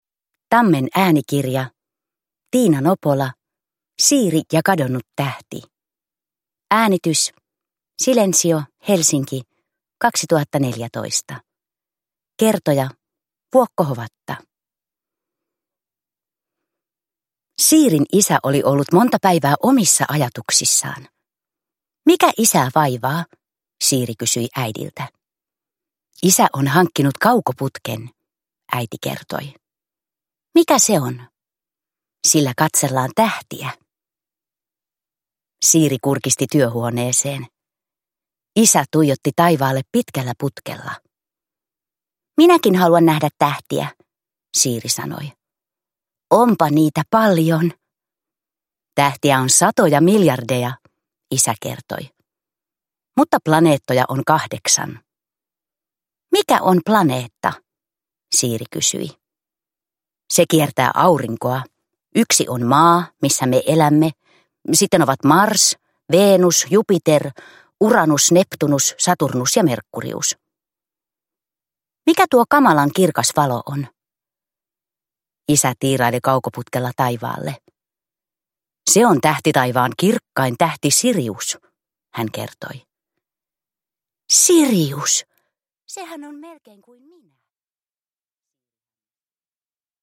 Siiri ja kadonnut tähti – Ljudbok – Laddas ner